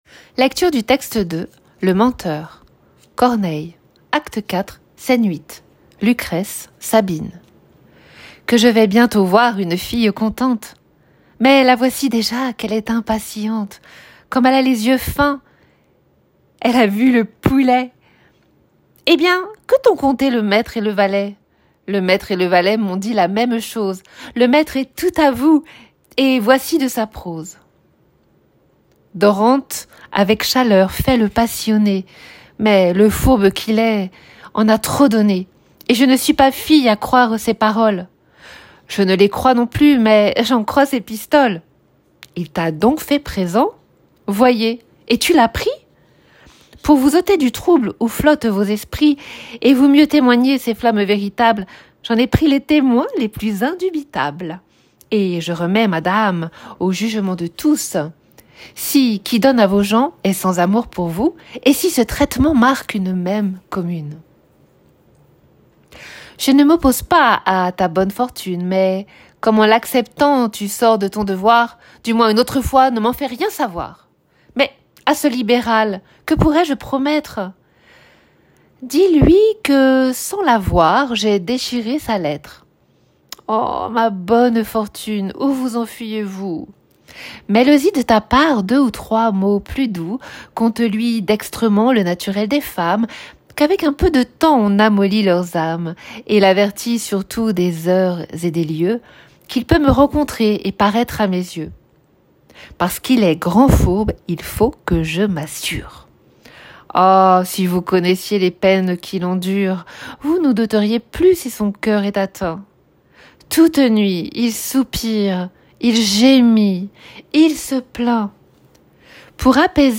En bas de cette page, vous pouvez écouter la lecture du texte de Corneille.
lecture_texte_2.m4a